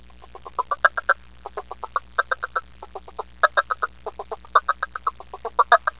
leopardfrog.aiff